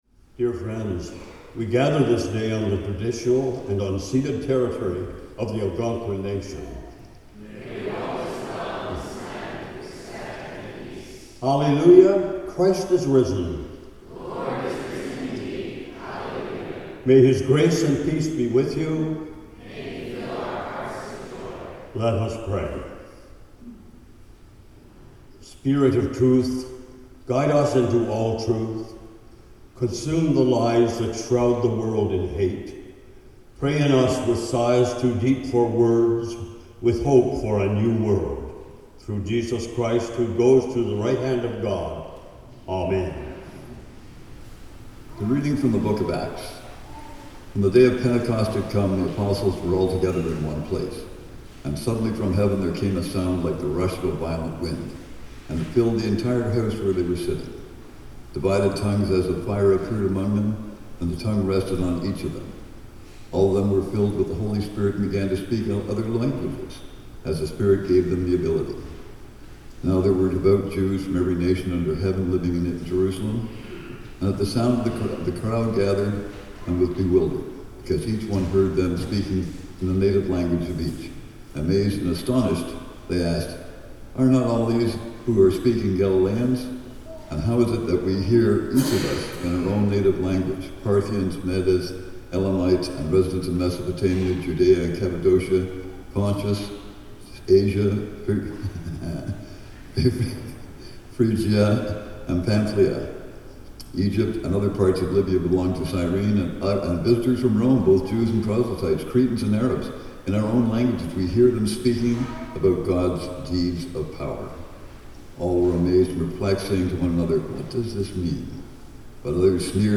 PENTECOST Hymn: “Come Down O Love Divine” (Common Praise #645 – words below) Land Acknowledgement, Greeting & Collect of the Day First Reading: Acts 2:1-21 Psalm 104:25-35, 37 – Lord, send forth your Spirit and renew the face of the earth Second Reading: Romans 8:22-27 (French) Hymn: “God...